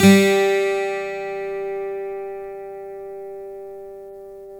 Index of /90_sSampleCDs/Roland - Rhythm Section/GTR_Steel String/GTR_12 String
GTR 12STR 03.wav